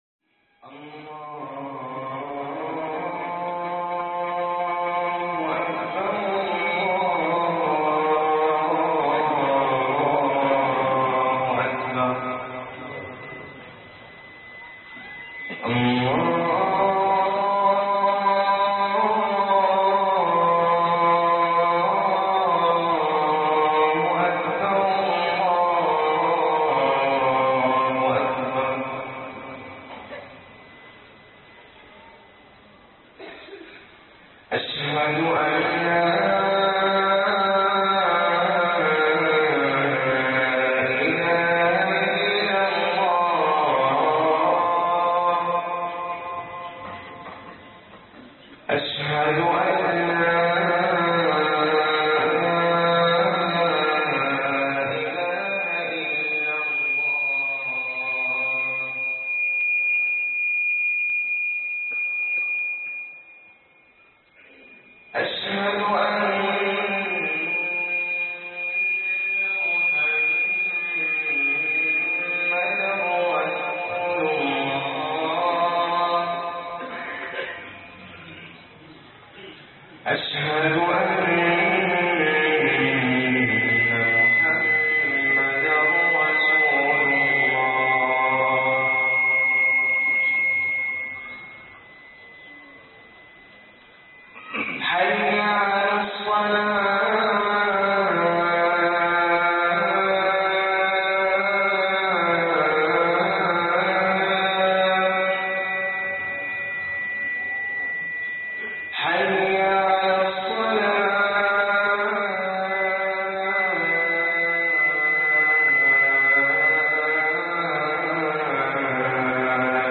التوبة - خطب الجمعة